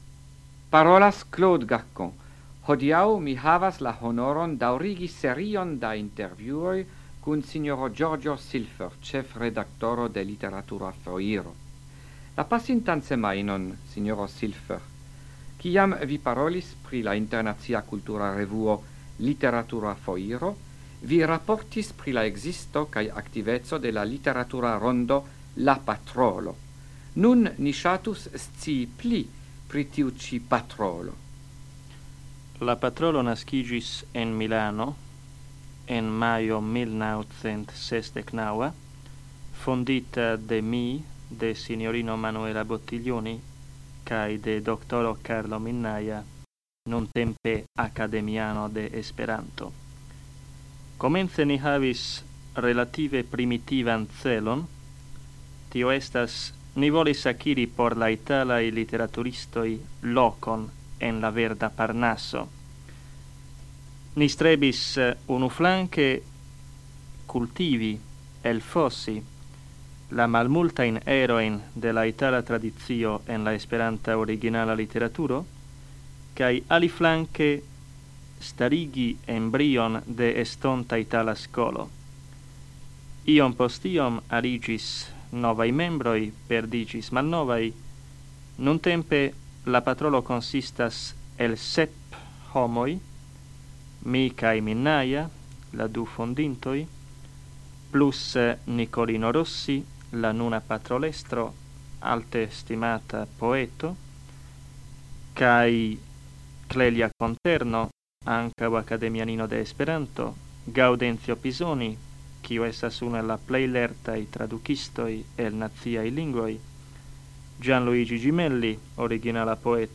Radioprelegoj en la jaro 1977
Intervjuo